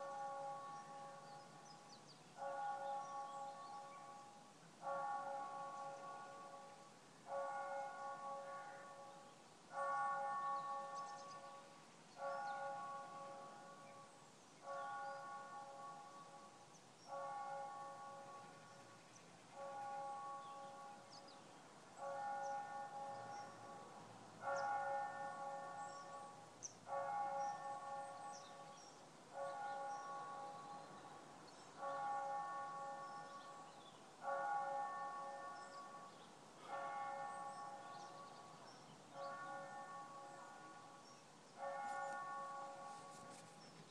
Church Bells.